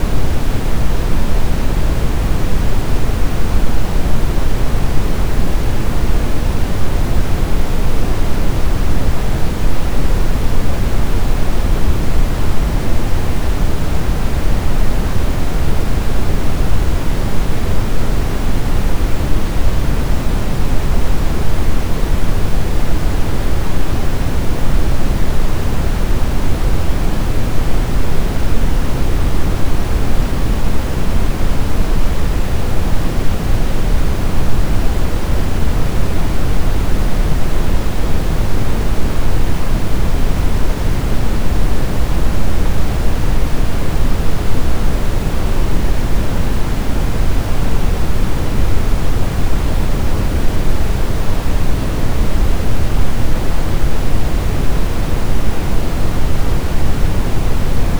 Oulun alueella on viime aikoina havaittu omituista radioliikennettä.